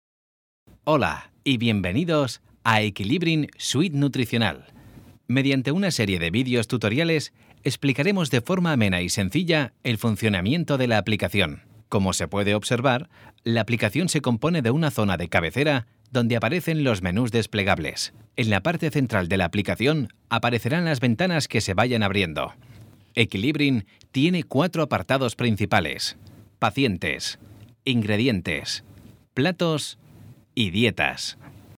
Voz joven para cuñas, spots de TV, doblajes y presentaciones.
Sprechprobe: eLearning (Muttersprache):